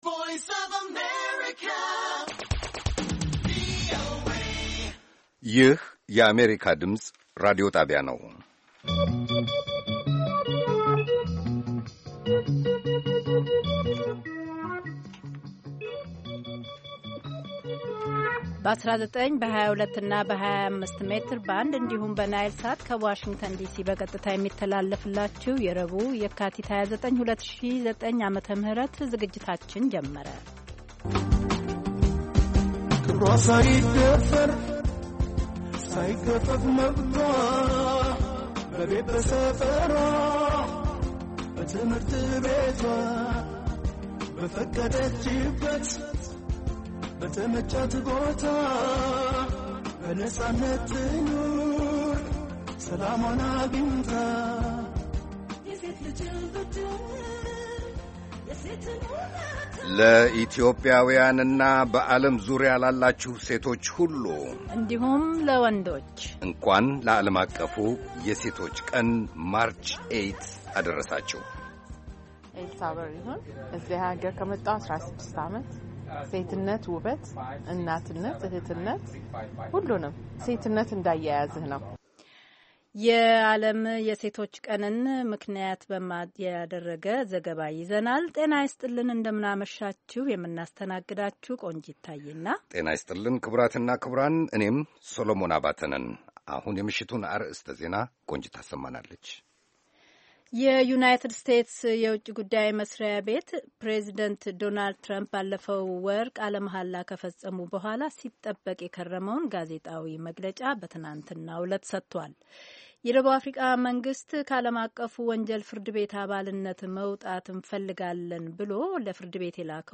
ረቡዕ፡-ከምሽቱ ሦስት ሰዓት የአማርኛ ዜና